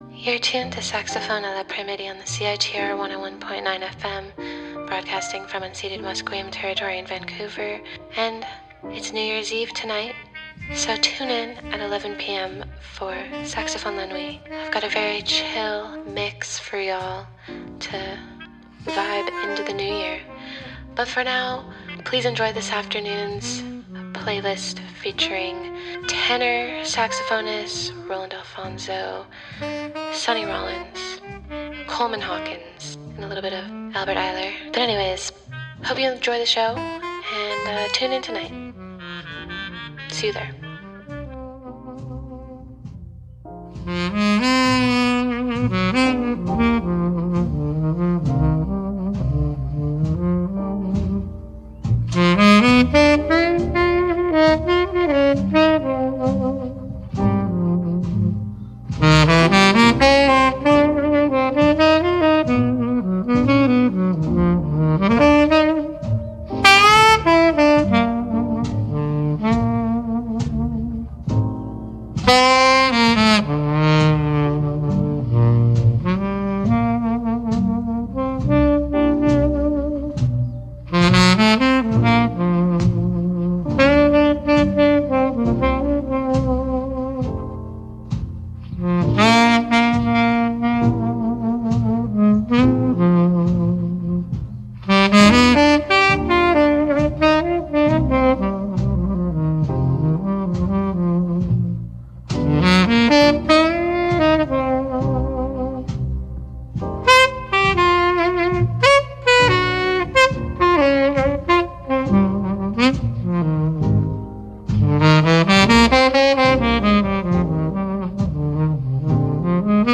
Featuring some of the greatest tenor saxophonists.